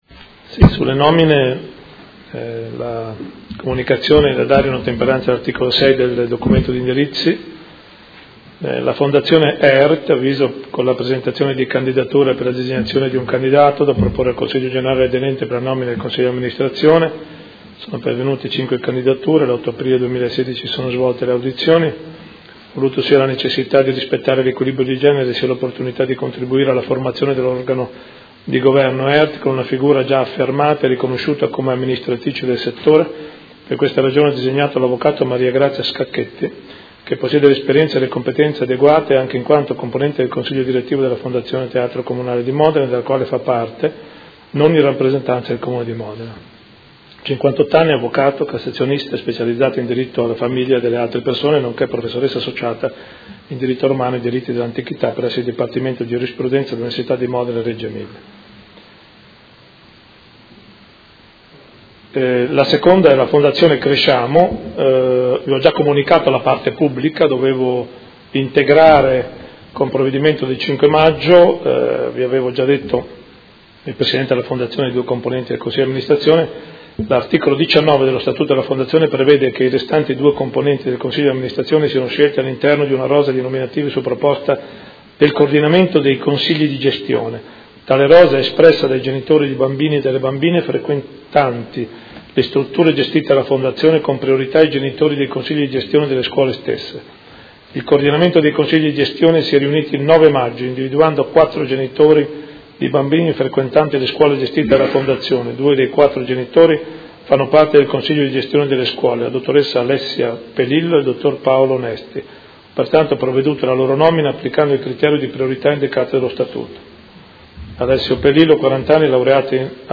Seduta del 12/05/2016. Comunicazioni del Sindaco su nomine e sgombero occupazioni abusive